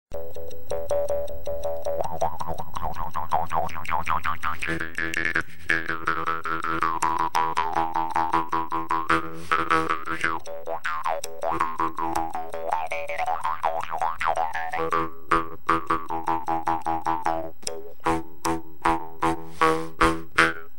Cambodian Bamboo Jew's Harps
UNTUNED
From Cambodia - We find these bamboo Jew's harps are easy to hold and may be longer lasting due to being made of thicker material than many other similar instruments. Held against the lips, they are easy to play and offer the same full, percussive sound as the "Kubings."
CambodianBamboo1.wma